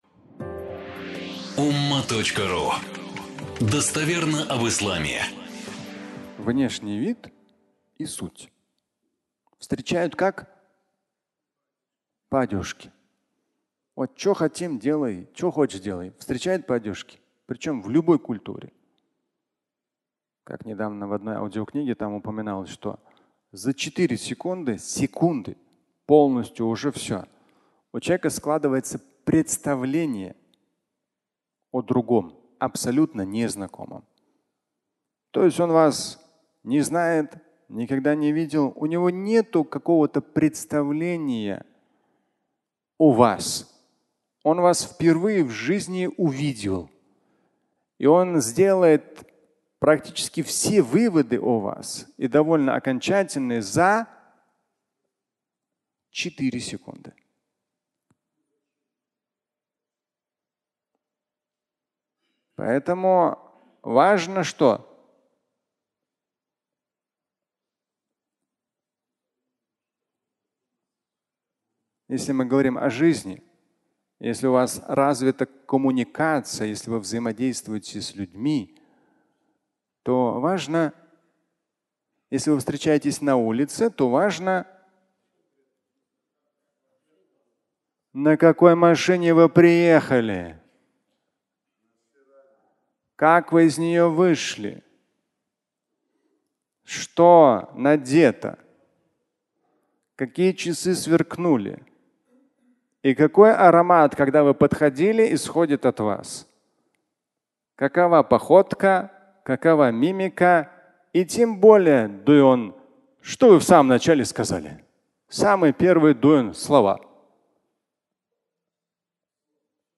Внешний вид и суть (аудиолекция)
Фрагмент пятничной лекции